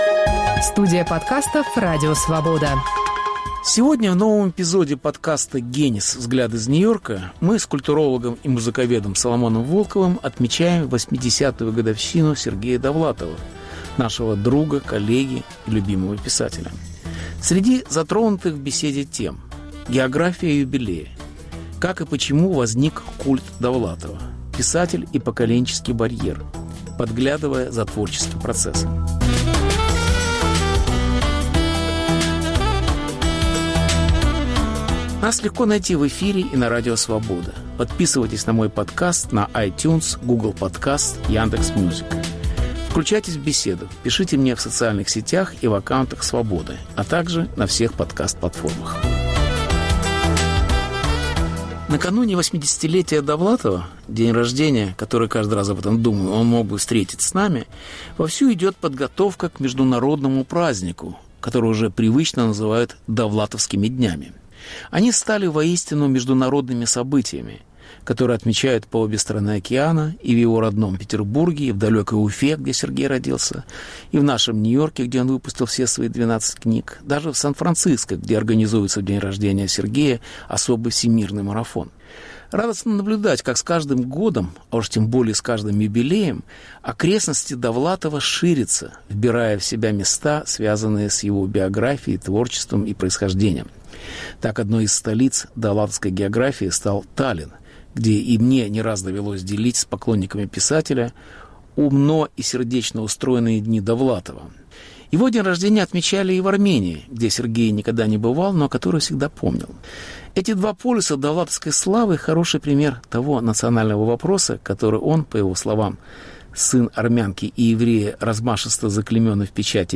Как отмечают 80-летие писателя. Беседа с Соломоном Волковым. Повтор эфира от 23 августа 2021 года.